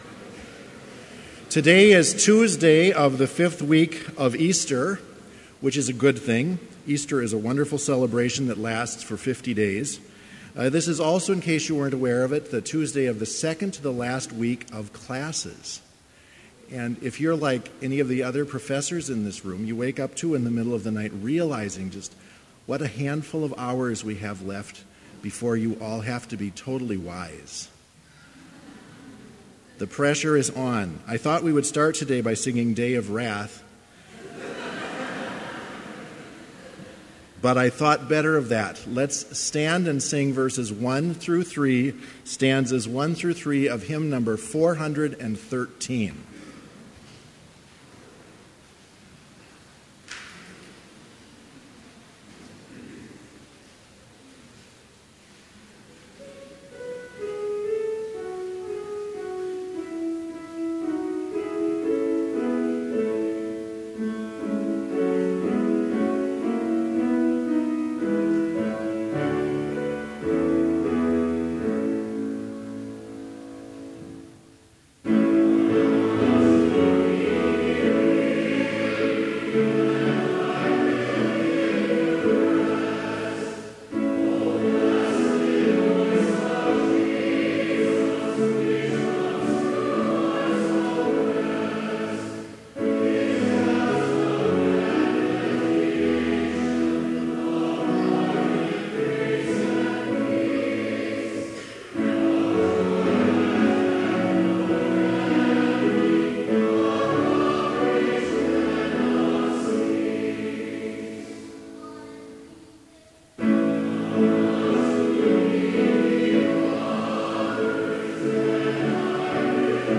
Chapel service on April 26, 2016, at Bethany Chapel in Mankato, MN
Complete service audio for Chapel - April 26, 2016